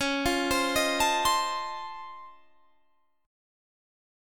Listen to C#+M9 strummed